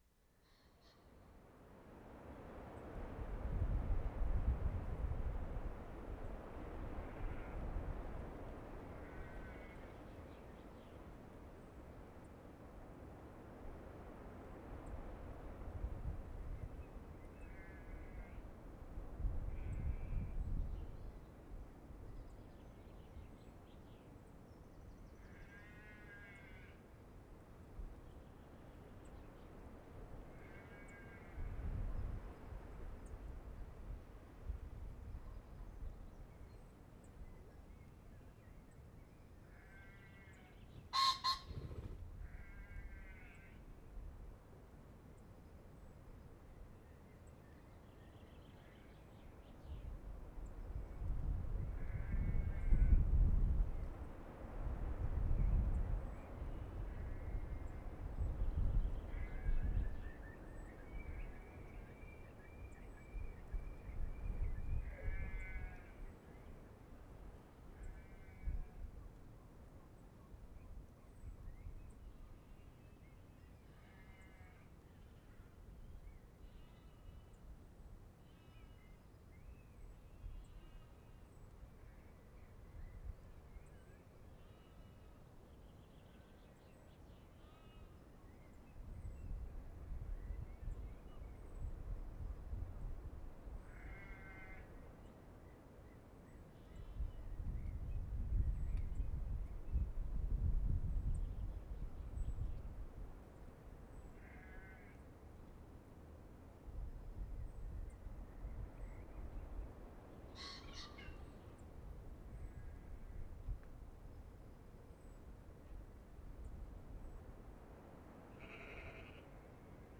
F 13-16. MALHAM TARN BIRD SANCTUARY
Same with strong gusts of wind.
mark * good pheasant [0:36]
15. Strong gusts of wind, sheep, birds and pheasant create strong atmosphere. Note (at last mark *) wing-flap after pheasant call.